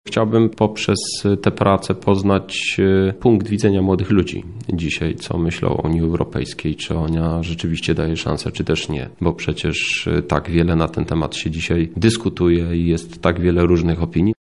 – mówi Europoseł Krzysztof Hetman.